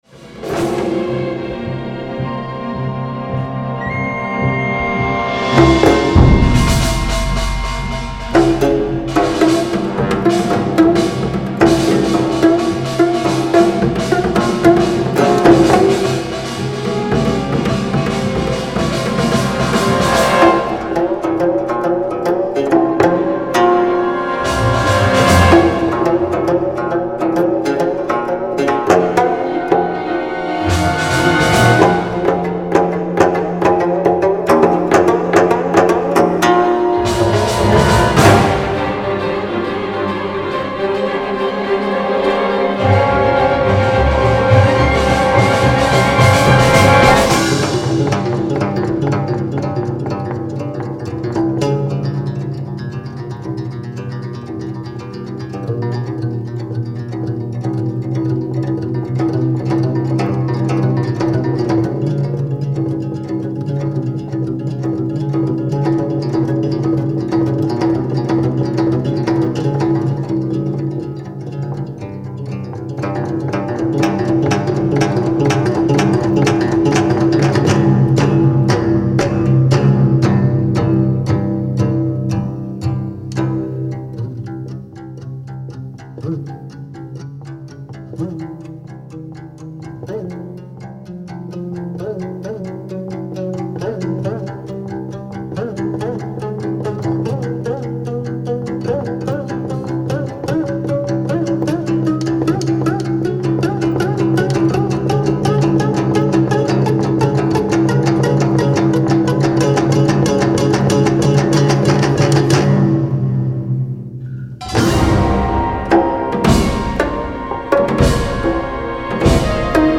concerto for 9-string geomungo & orchestra